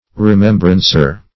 Remembrancer \Re*mem"bran*cer\ (-bran-s?r), n.